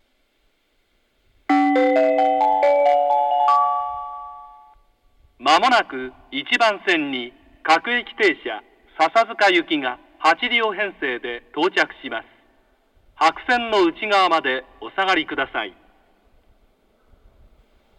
shinjuku-sanchome-1p01_local_sasazuka_8.mp3